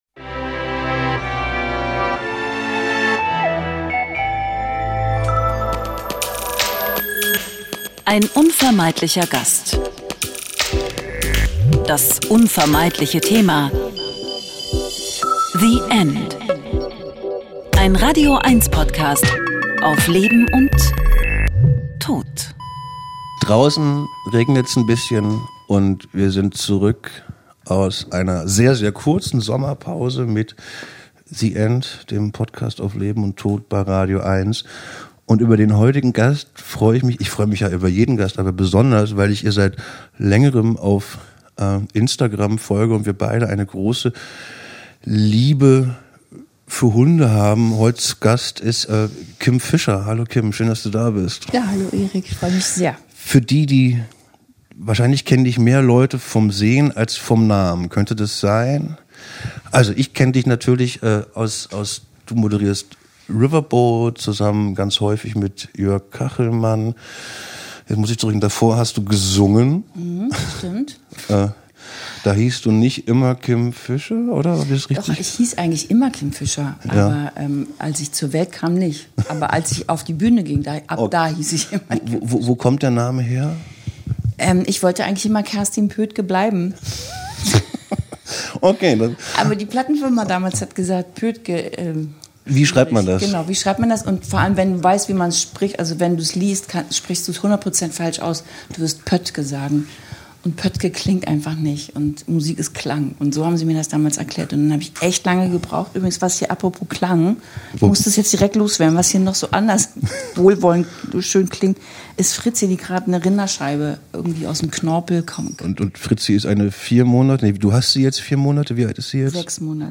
Ein Gespräch über Tabus, Kommunikation, gute Krankenhäuser, Patientenverfügungen, aber auch über die Angst vor dem Sterben.